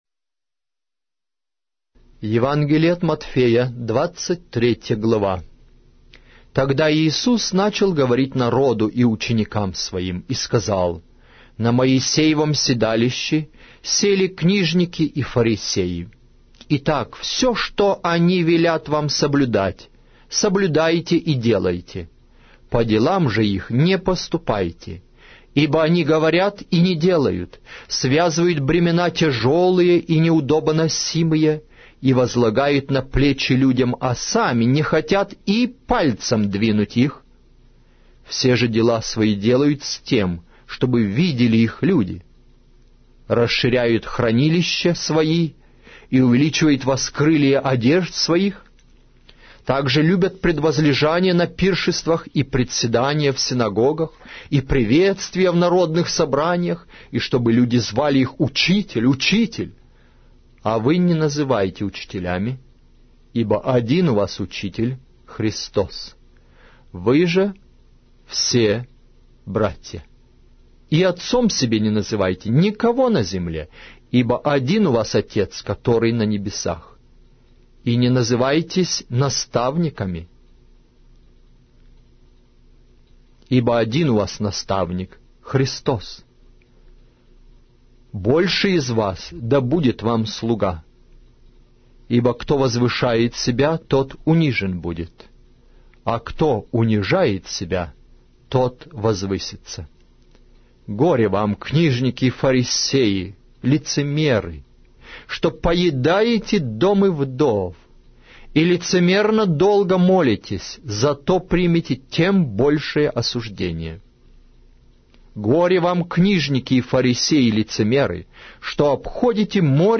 Аудиокнига: Евангелие от Матфея